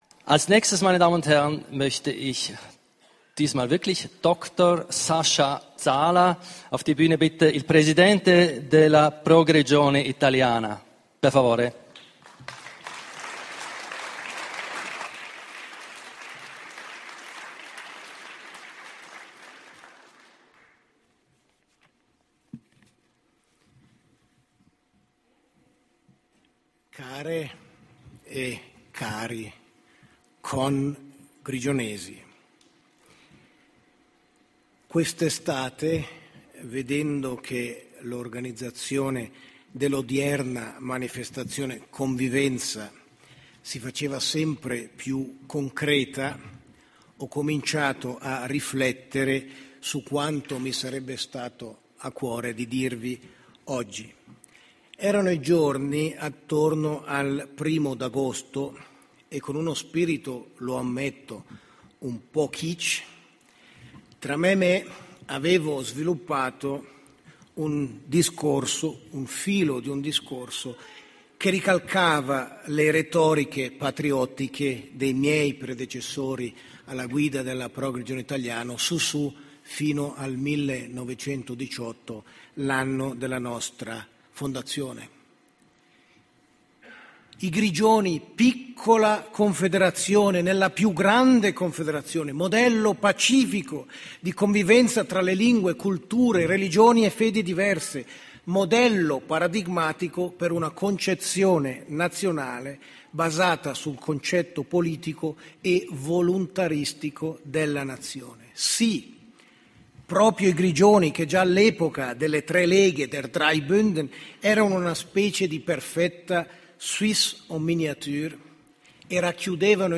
Quando dissi «oggi, noi romanci e noi grigionitaliani siamo feriti e tristi» il pubblico si scatenò e capii che avevo raccolto i sentimenti di tutti i presenti.
file audio del discorso tenuto il 16 ottobre 2010 a Cazis